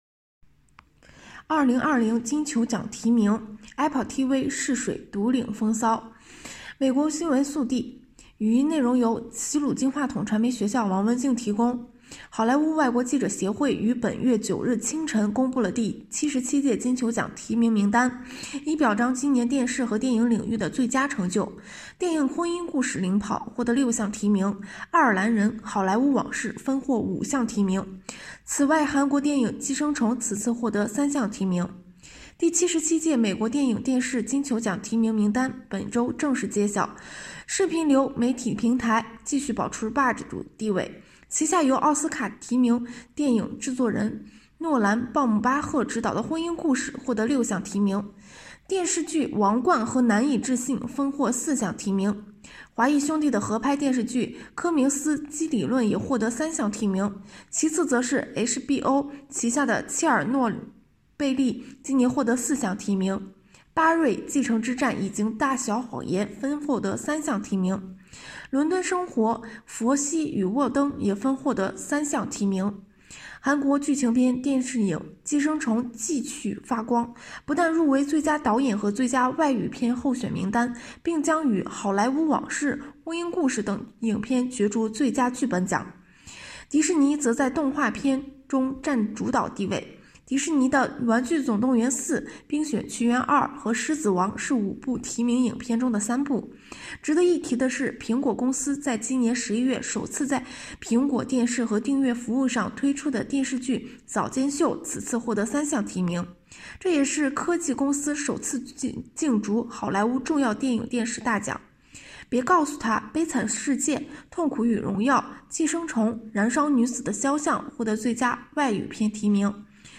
洛杉矶报道